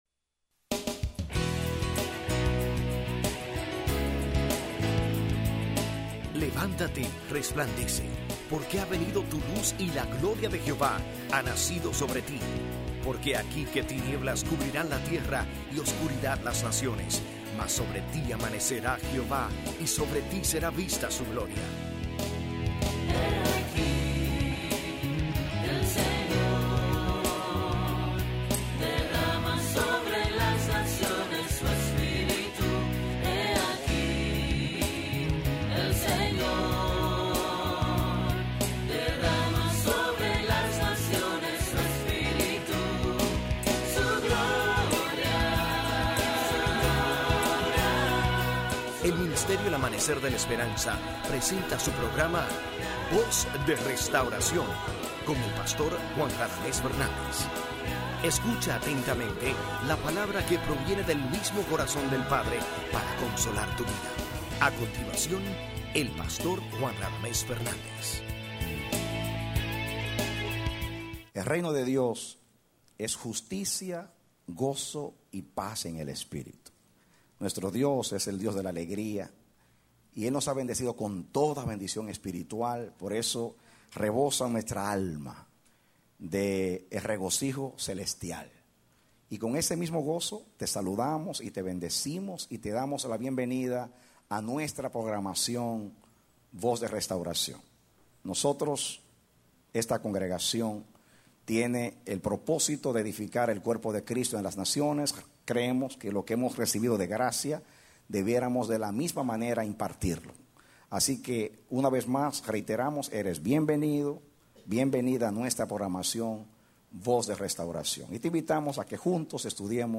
A mensajes from the series "Mensajes." Predicado Agosto 26, 2007